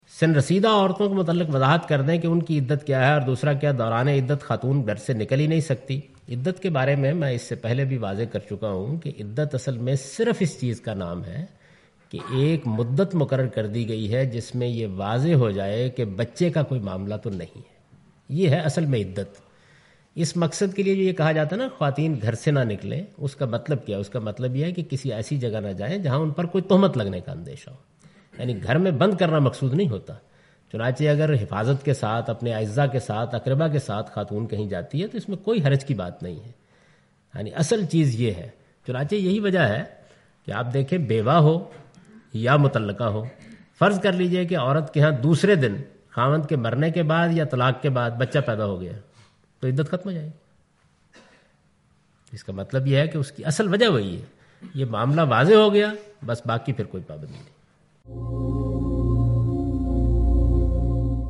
Question and Answers with Javed Ahmad Ghamidi in urdu